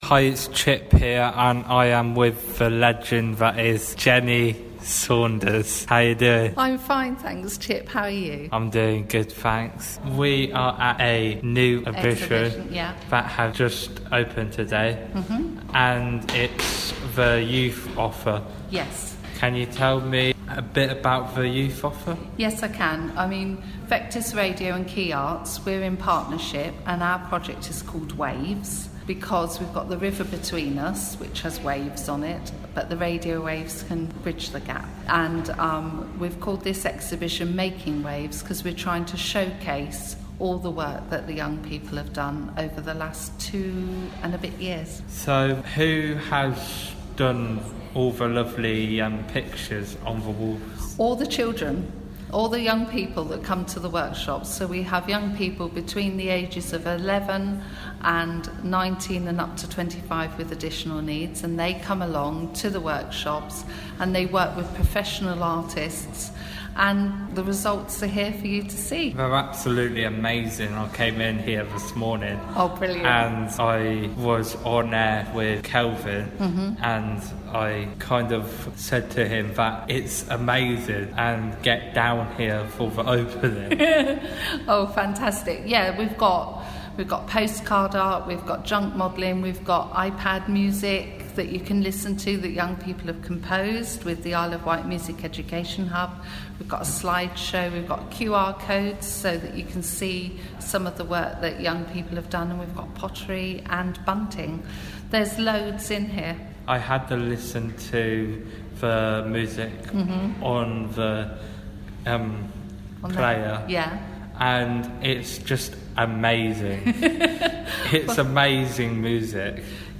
Art Interview